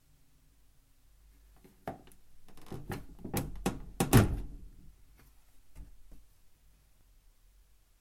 Three piece Mirrored Cabinet Door
Duration - 8 s Environment - Absorption materials, open space. Description - Left Door Plastic cabinet, mirrored doors, Clips and clatters when opening.